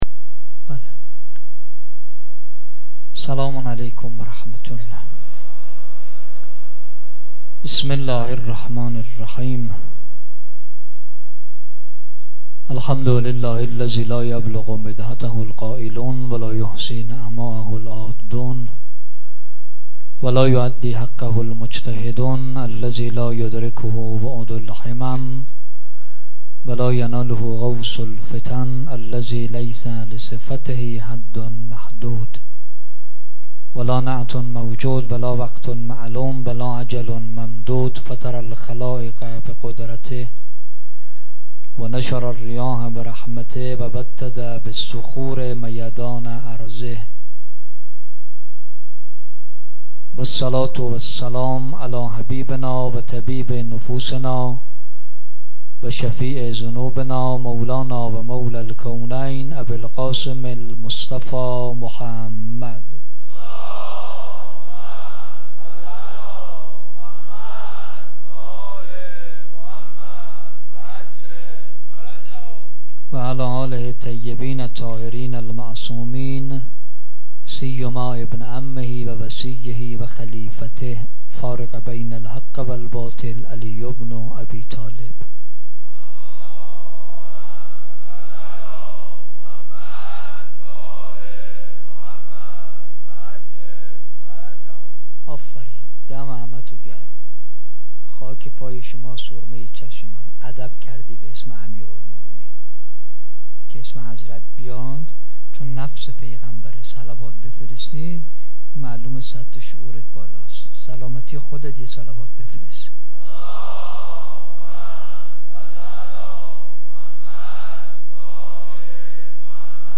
سخنرانی
شب هشتم محرم